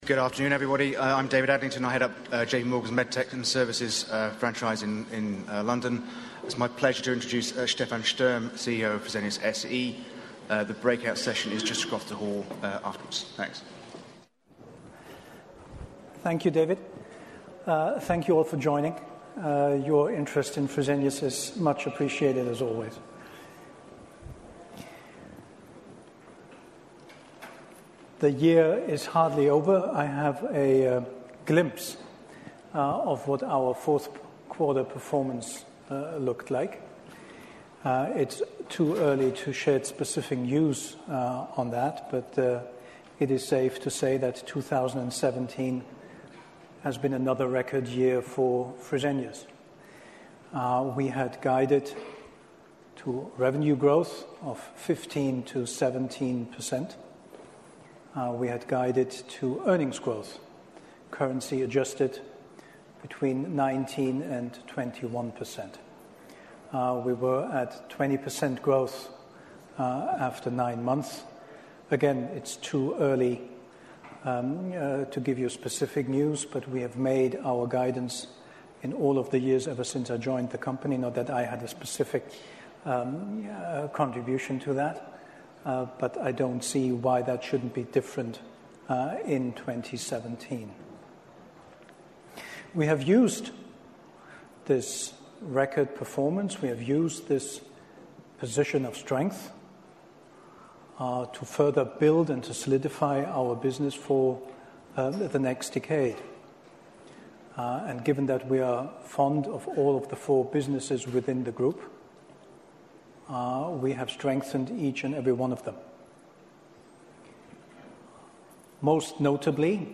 Telefonkonferenz Q3 2019, Fresenius